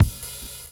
Index of /90_sSampleCDs/Drumdrops In Dub VOL-1/SINGLE HITS/DUB KICKS
KICK+HAT-03.wav